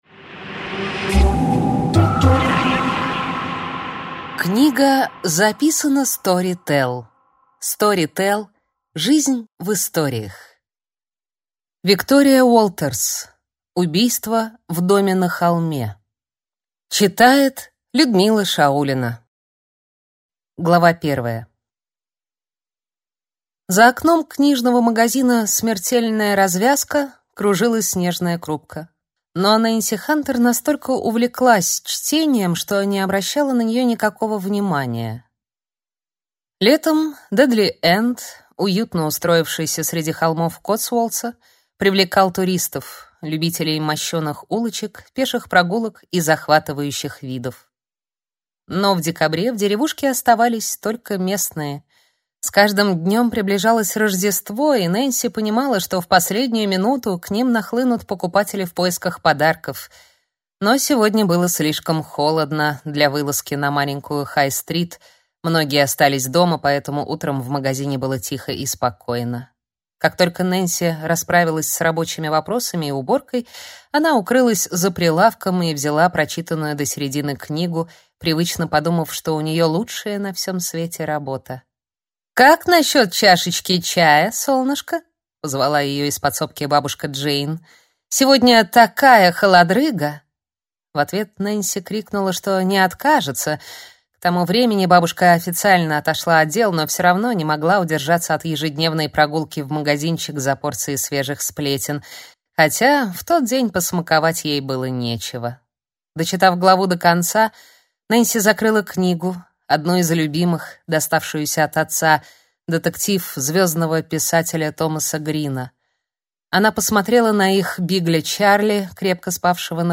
Аудиокнига Убийство в доме на холме | Библиотека аудиокниг